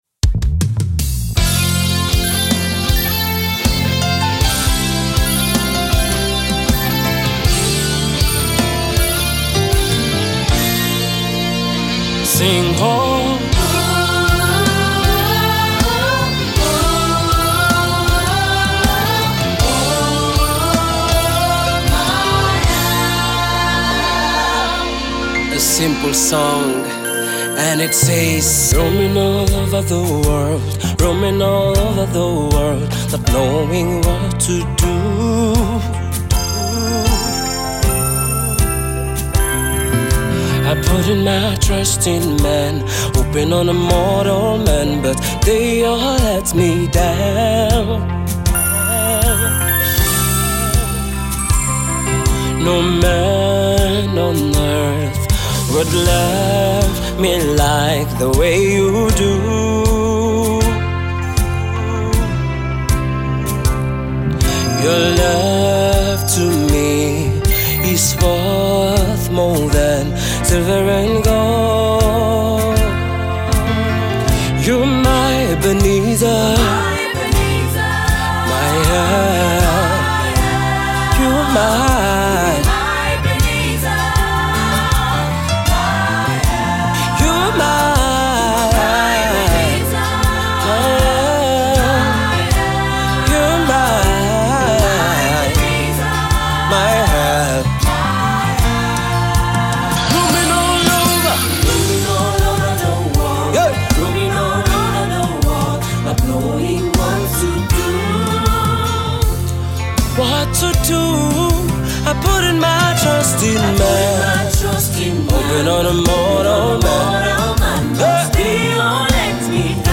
song of praise